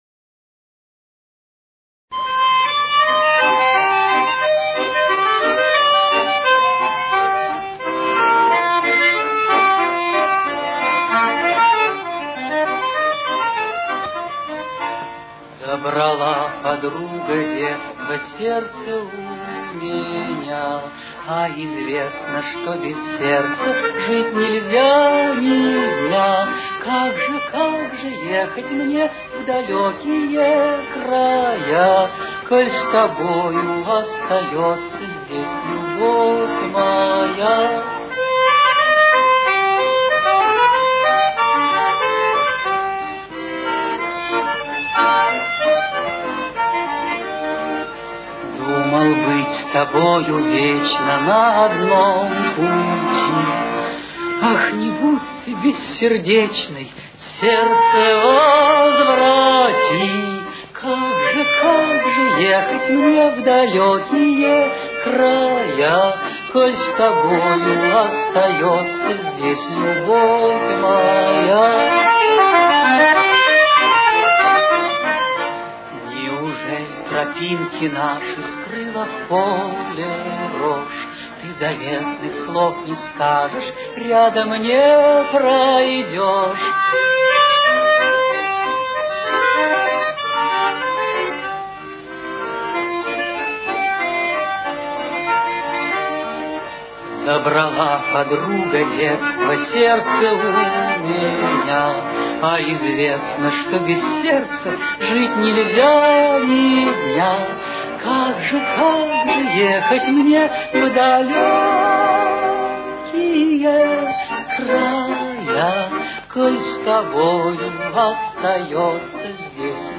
с очень низким качеством (16 – 32 кБит/с)
актёр)Тональность: Соль минор. Темп: 90.